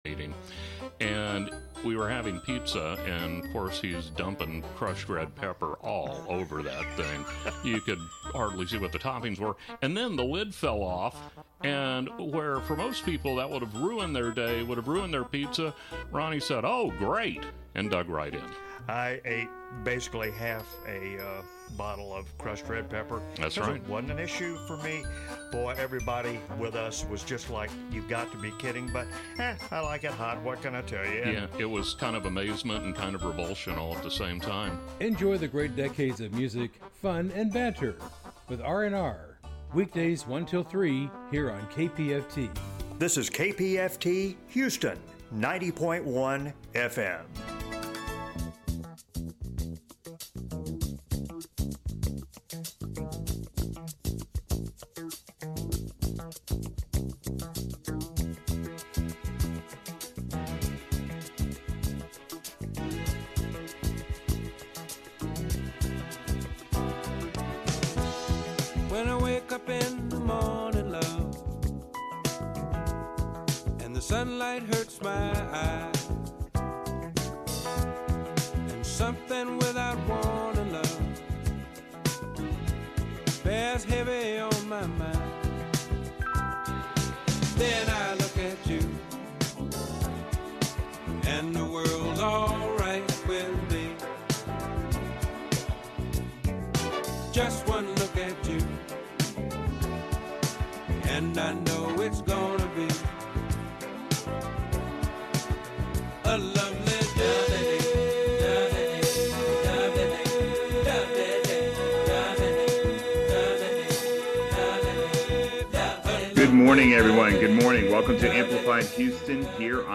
90.1 KPFT Interview - Civic Heart